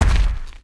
boar_walk.wav